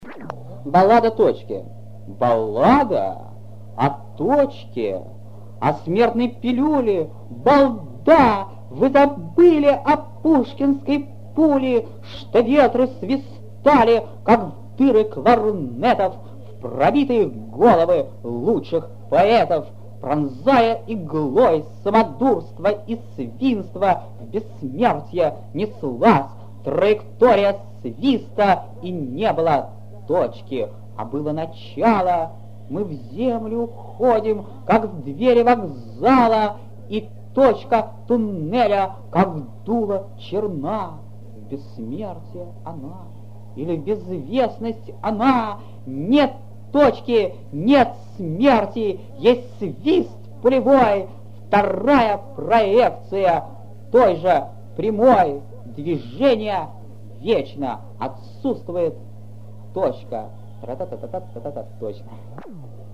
Молодой Вознесенский читает свои стихи.
Вознесенский читал в своей неповторимой манере, нажимая на ударные слоги, а другие, вместе с согласными, растягивая, словно подпевая.